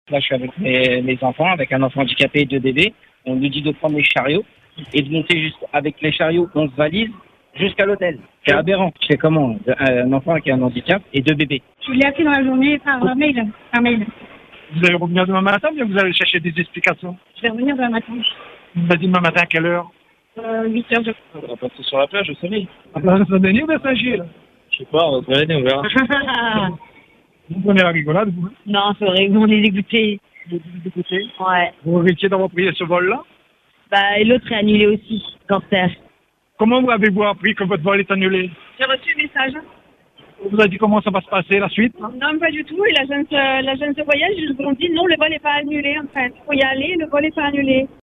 est allé à la rencontre des passagers bloqués, entre fatigue et résignation.